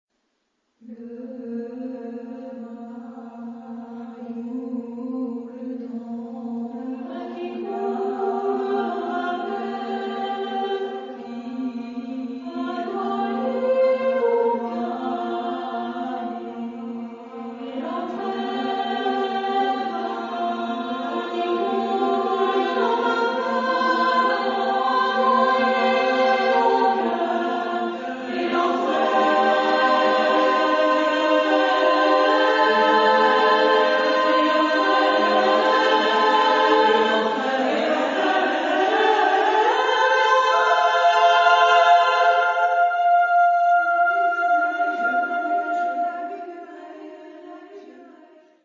Género/Estilo/Forma: Profano ; Poema ; contemporáneo
Carácter de la pieza : sensual ; calma
Tipo de formación coral: SSMAA  (5 voces Coro femenino )
Tonalidad : diversas